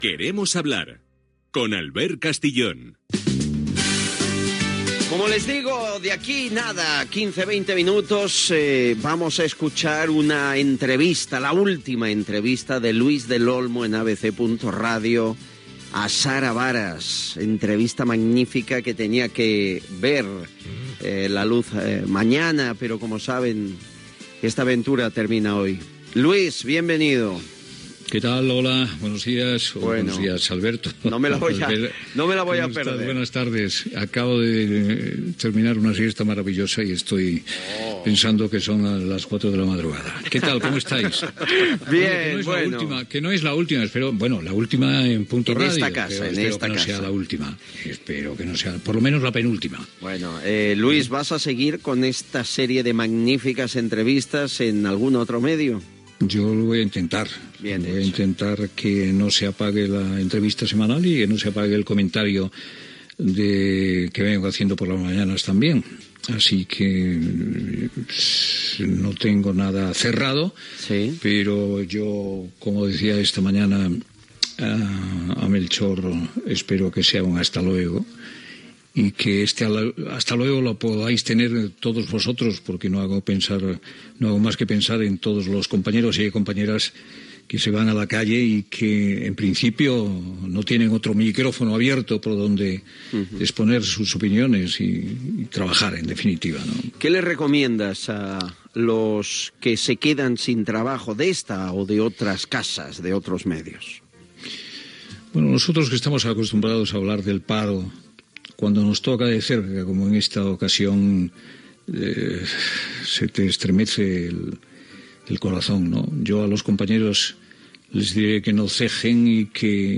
Fragment d'una conversa amb Luis del Olmo, el darrer dia d'emissió de l'emissora
Entreteniment